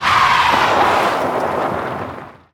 CosmicRageSounds / ogg / general / highway / oldcar / shriekstop.ogg
shriekstop.ogg